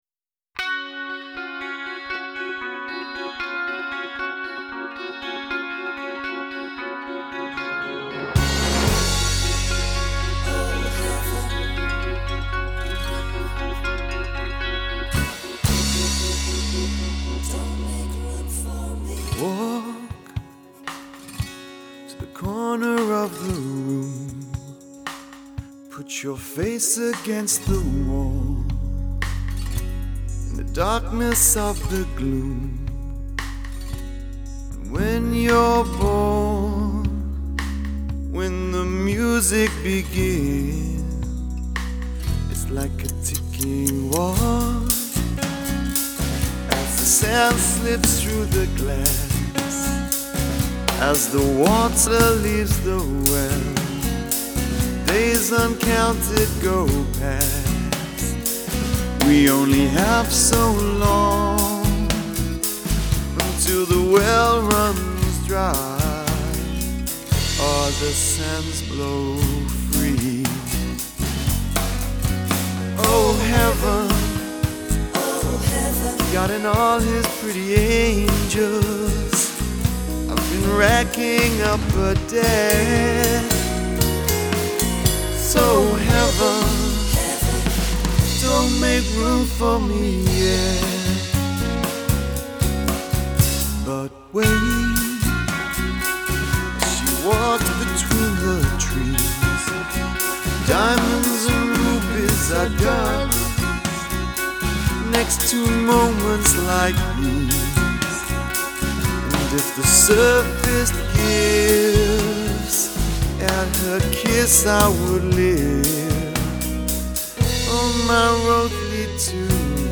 Drums
Guitars, keyboards and Backing vox
Tech nerds may like to know that the guitar intro part uses one patch – no other instruments.